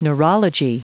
Prononciation du mot neurology en anglais (fichier audio)
Prononciation du mot : neurology